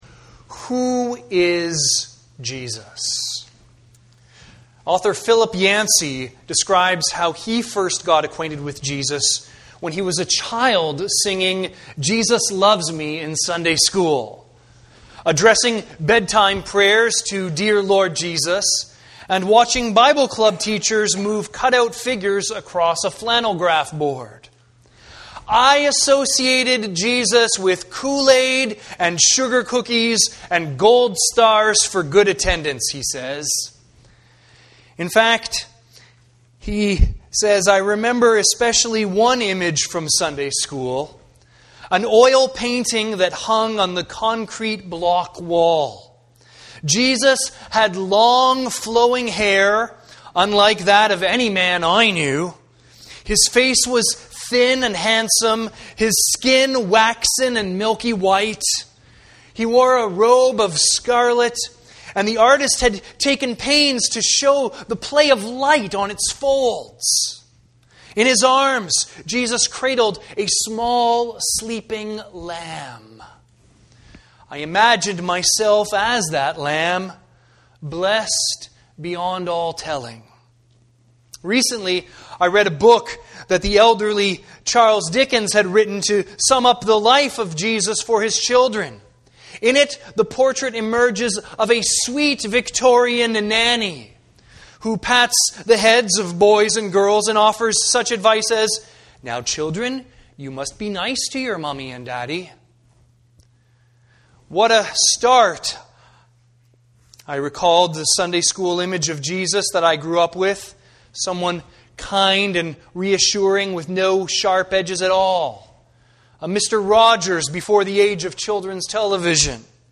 Sermons | Campbell Baptist Church
View the Sunday service.